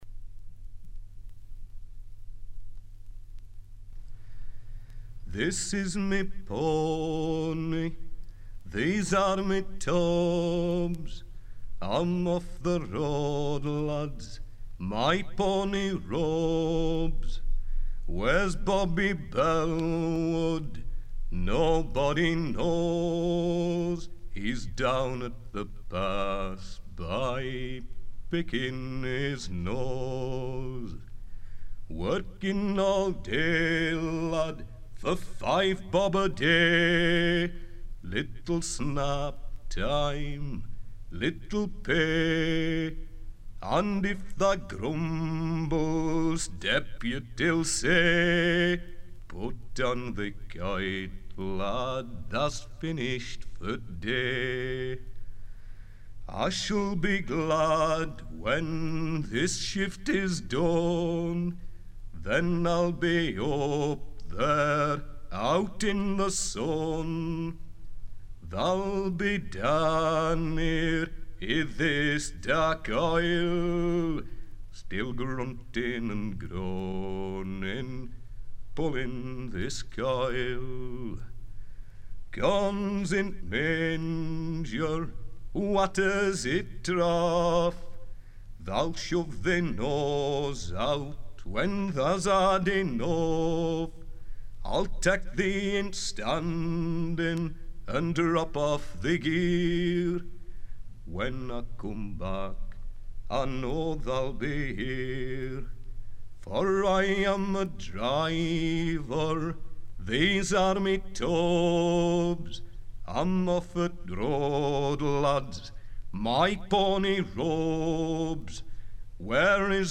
folk singer
his fine voice and lively interpretation of traditional English songs seemed to me to put him in the first rank of British singers.